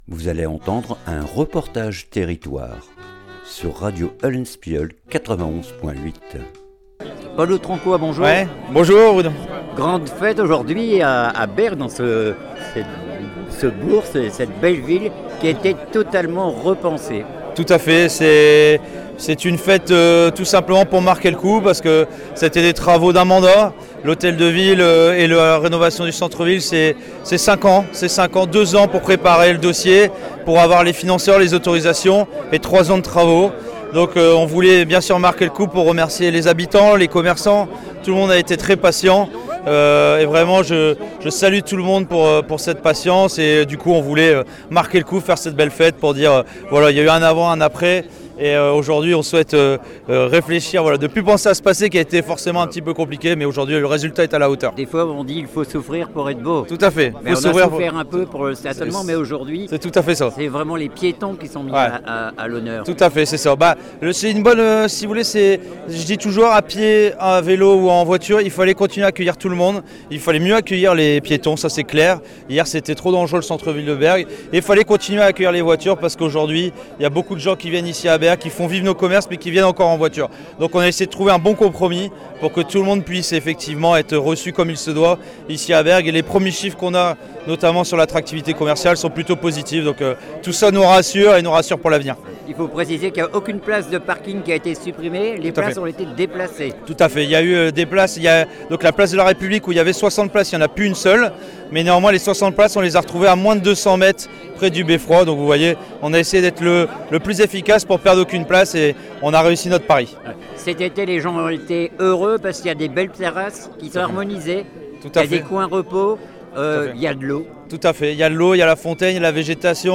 REPORTAGE TERRITOIRE BERGUES INAUGURATION DES PLACES
INAUGURATION DES PLACES ET DE L HOTEL DE VILLE OCTOBRE 2025